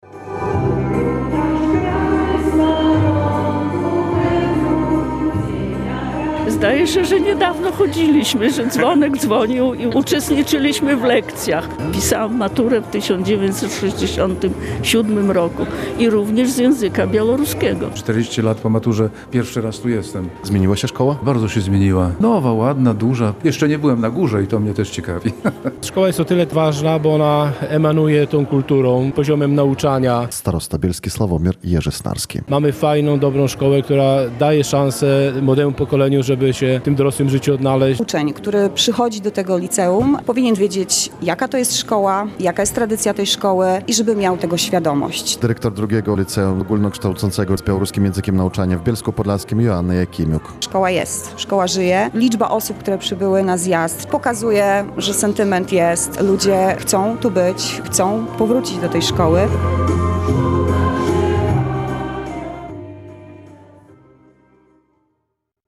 Jak i w trakcie każdej szkolnej uroczystości, absolwenci zaśpiewali wspólnie hymn szkoły.